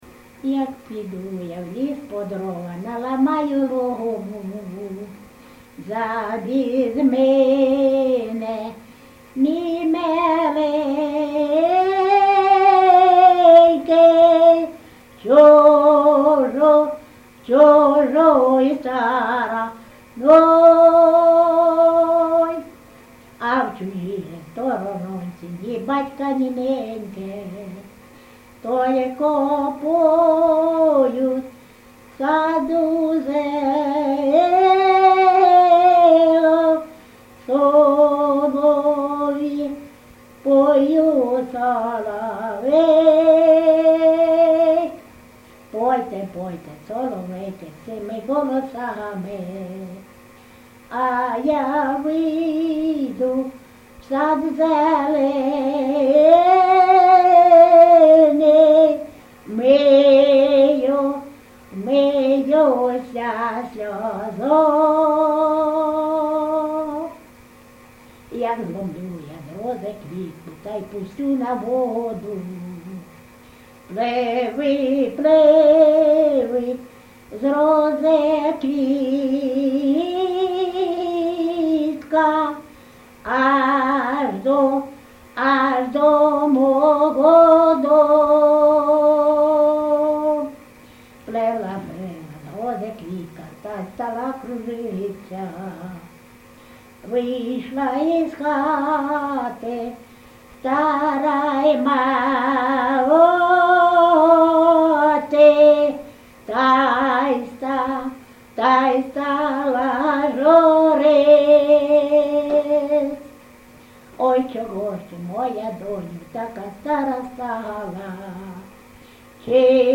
ЖанрПісні з особистого та родинного життя
МотивНещаслива доля, Родинне життя, Чужина, Журба, туга
Місце записум. Сіверськ, Артемівський (Бахмутський) район, Донецька обл., Україна, Слобожанщина